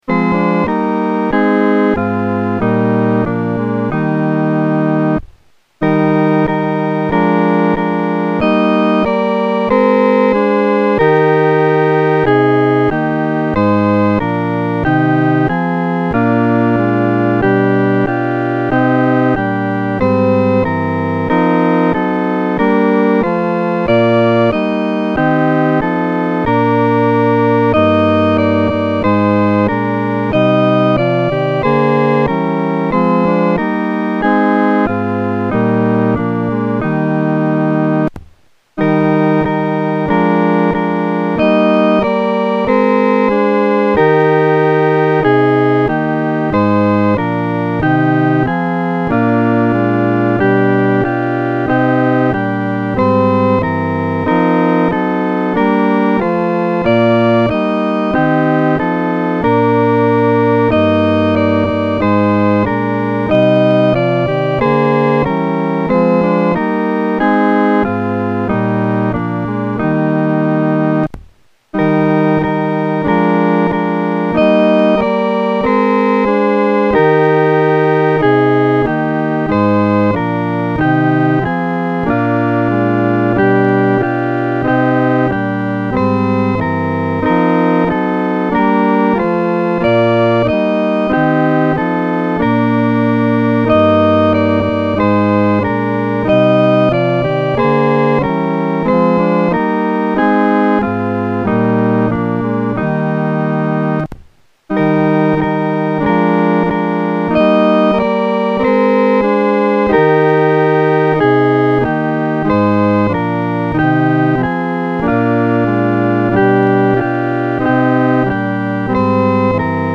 伴奏
本首圣诗由网上圣诗班 (南京）录制